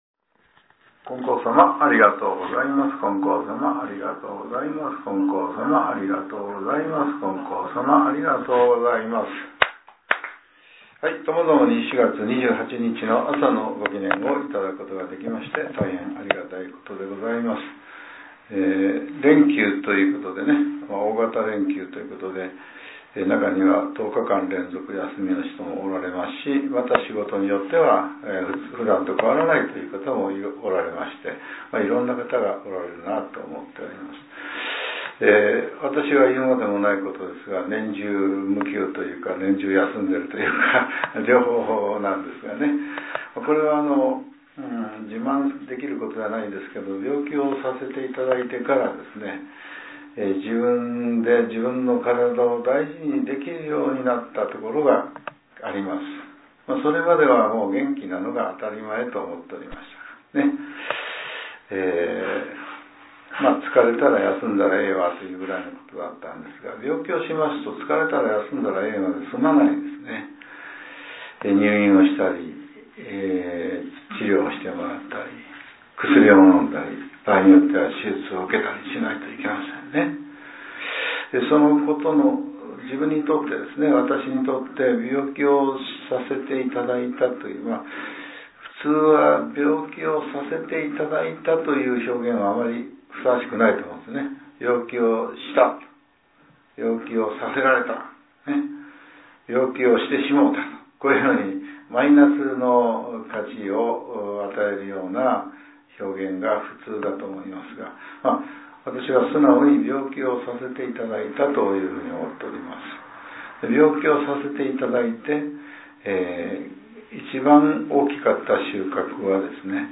令和７年４月２８日（朝）のお話が、音声ブログとして更新されています。